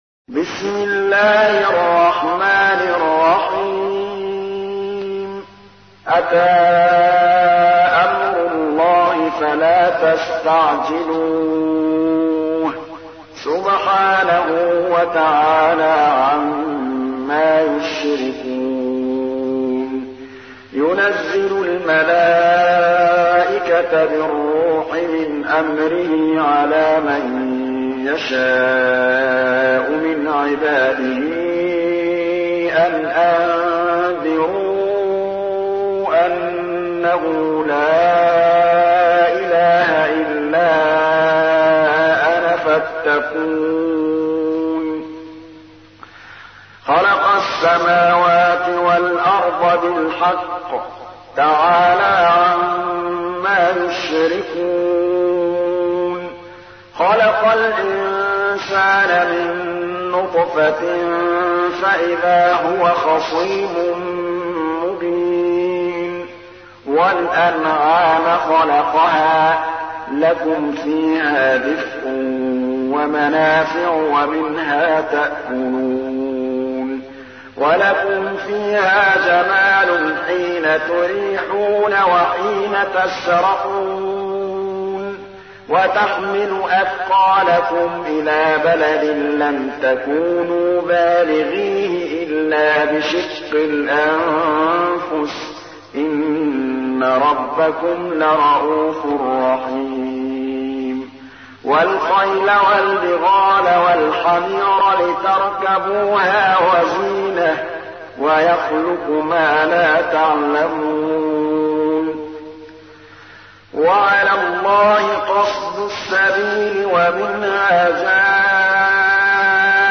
تحميل : 16. سورة النحل / القارئ محمود الطبلاوي / القرآن الكريم / موقع يا حسين